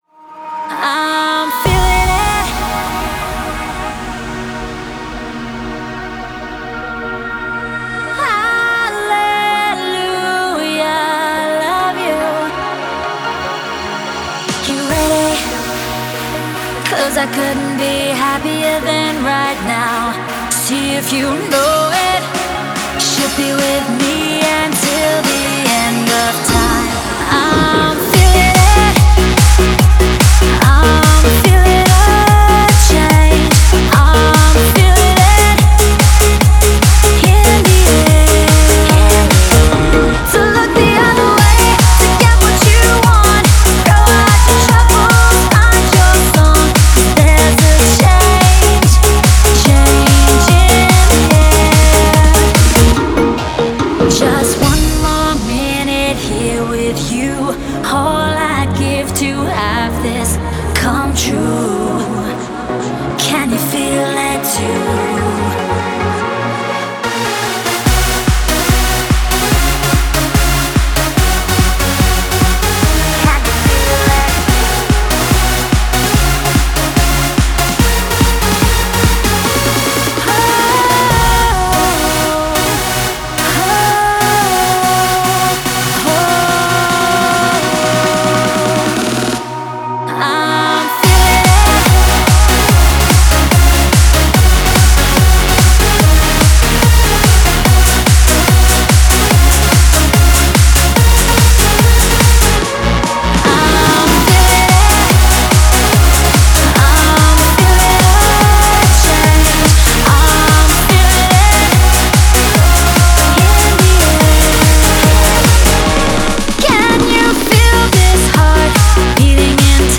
это энергичная танцевальная композиция в жанре евродэнс